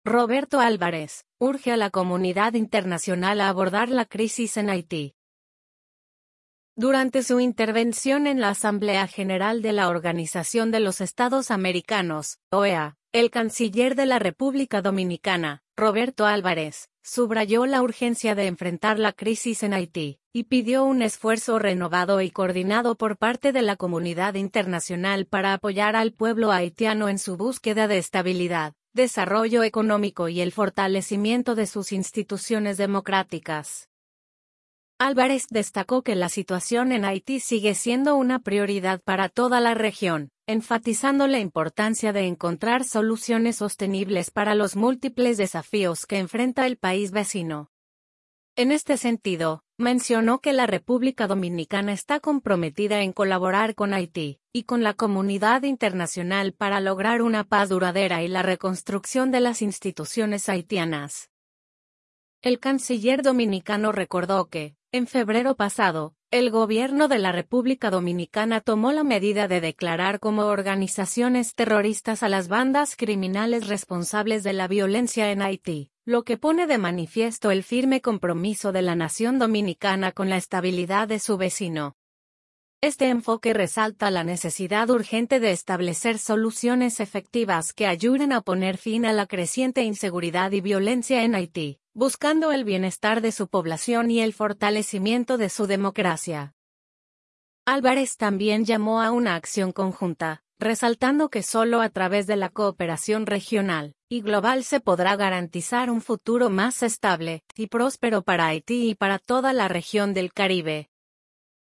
Durante su intervención en la Asamblea General de la Organización de los Estados Americanos (OEA), el canciller de la República Dominicana, Roberto Álvarez, subrayó la urgencia de enfrentar la crisis en Haití y pidió un esfuerzo renovado y coordinado por parte de la comunidad internacional para apoyar al pueblo haitiano en su búsqueda de estabilidad, desarrollo económico y el fortalecimiento de sus instituciones democráticas.